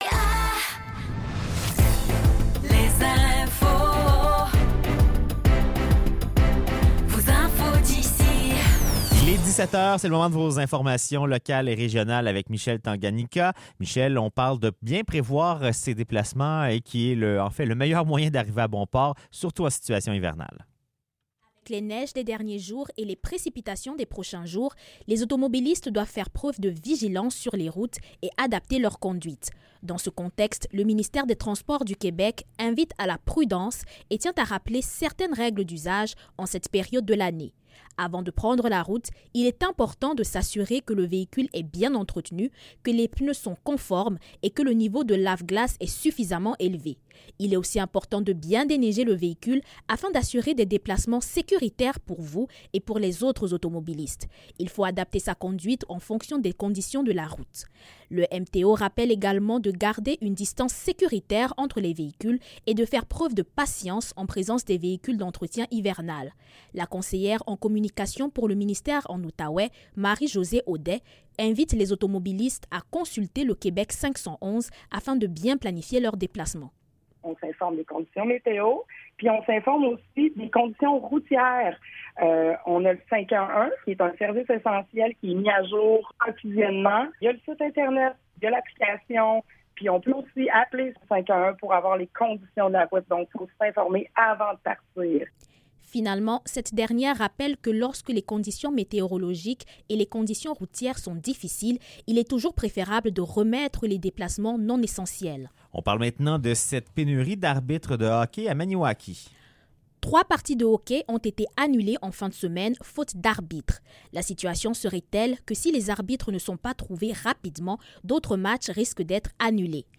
Nouvelles locales - 10 décembre 2024 - 17 h